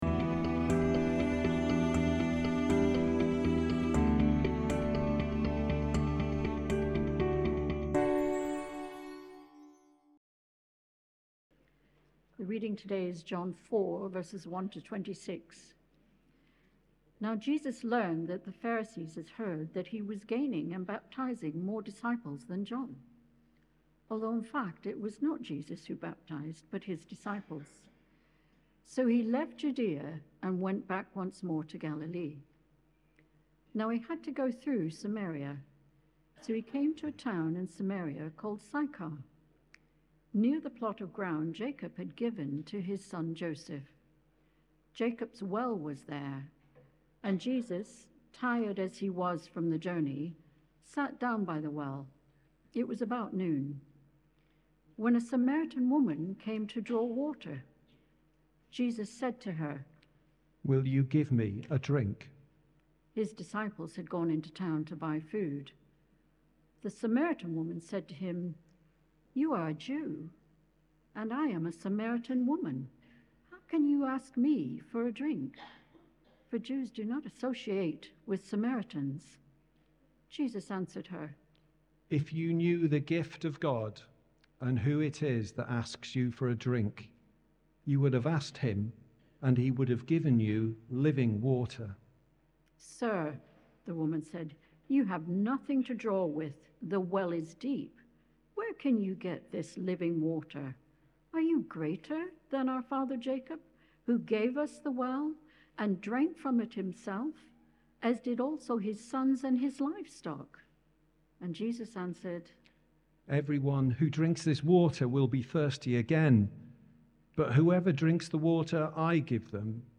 2025-10-26 Sunday Talk – Worship 3